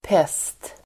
Uttal: [pes:t]